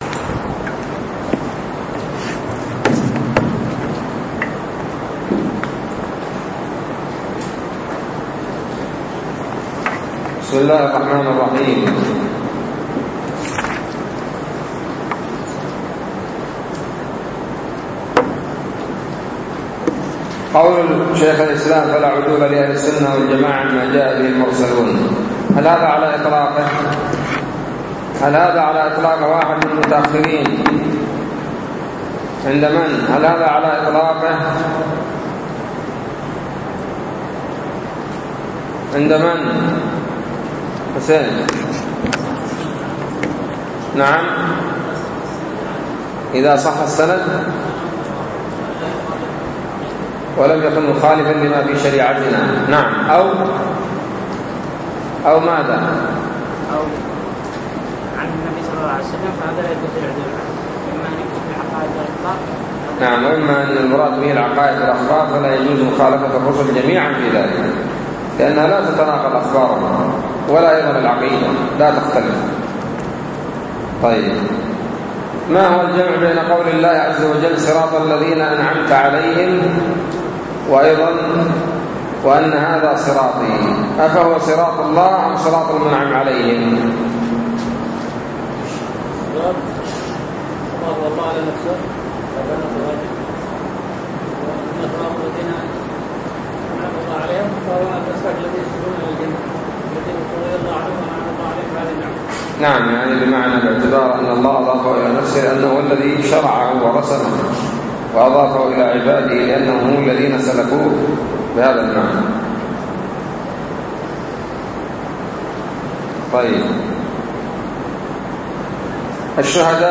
الدرس الثاني والثلاثون من شرح العقيدة الواسطية